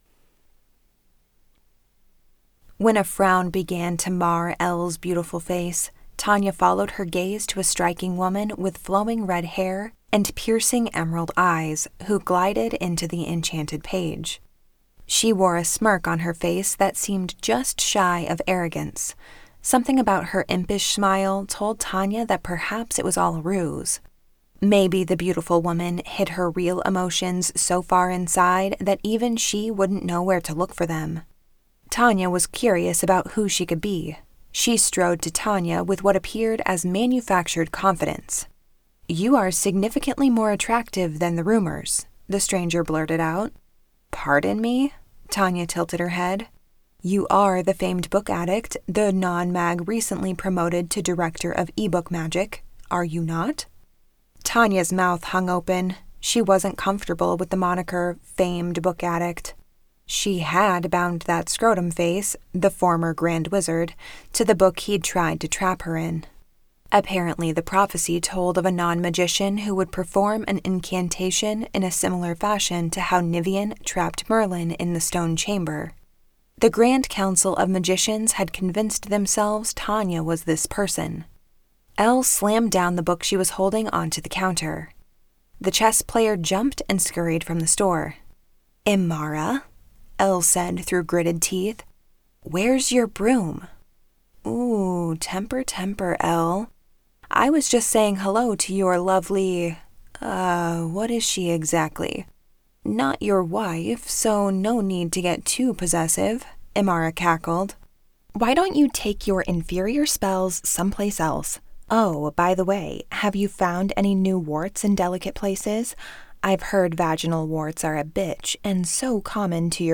The Book Witch by Annette Mori [Audiobook]
Narrator: Ashley Clements